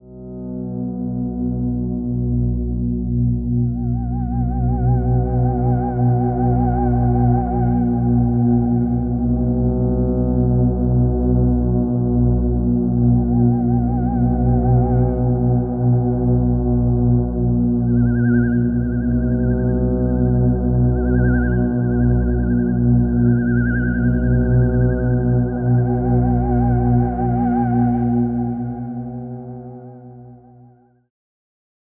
Tag: 预告片 电影 电影院 剧院 剧院